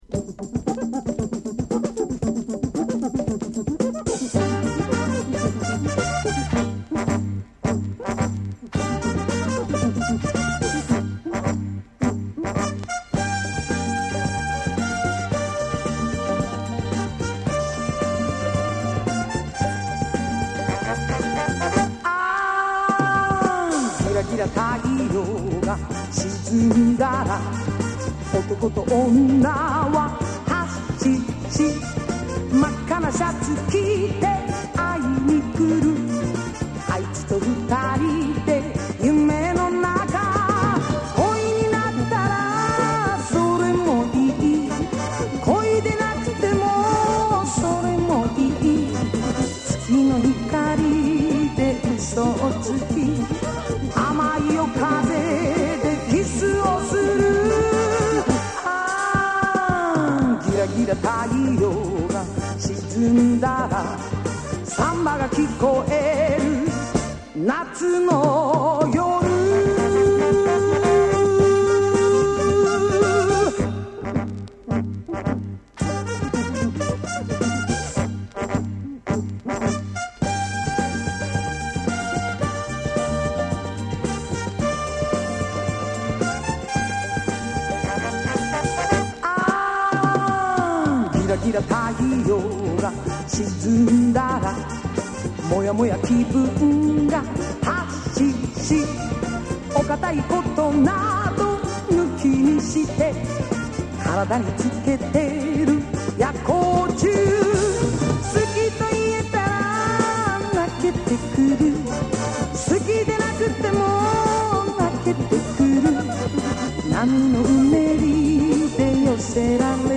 盤に薄いスリキズ有/音の薄い部分で若干チリノイズ有
DJプレイ可能な和モノ作品の多い和製リズム・アンド・ブルーズ歌謡女王